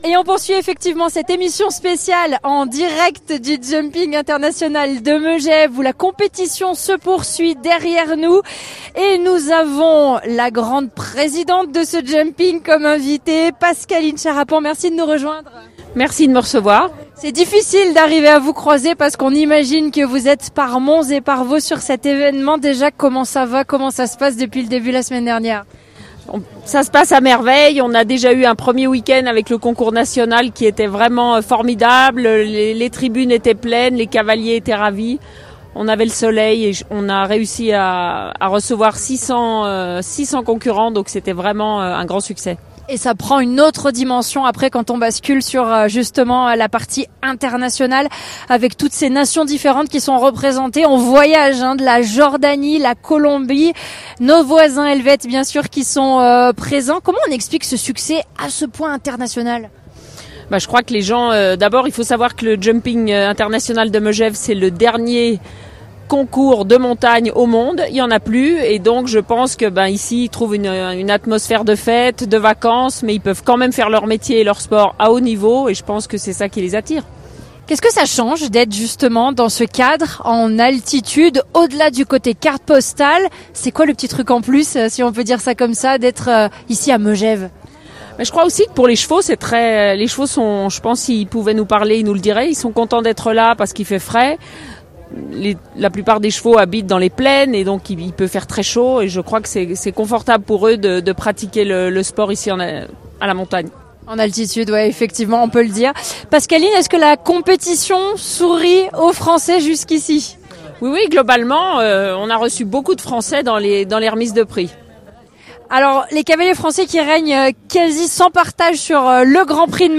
Partenaire de l’événement, nous étions en émission spéciale en direct, pour faire vivre aux auditeurs toute l’ambiance de cette grande fête du sport et de l’élégance.
Interview